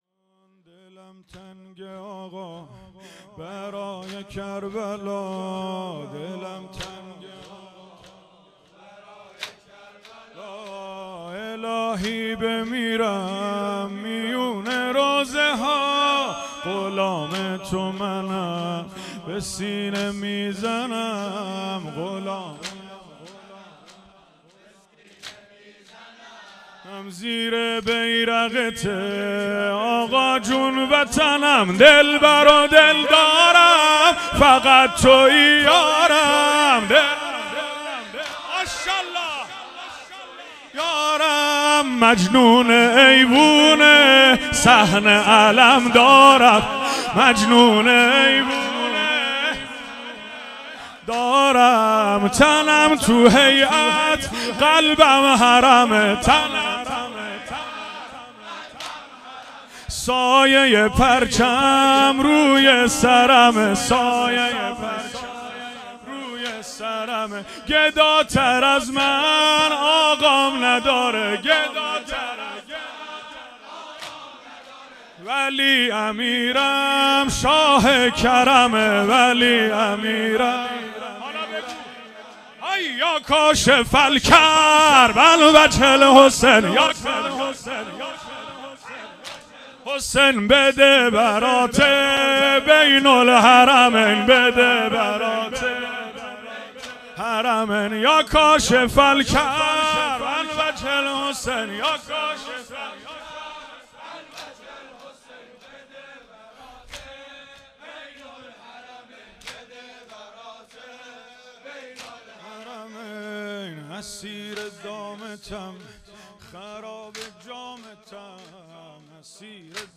دهه اول محرم الحرام ۱۴۴۴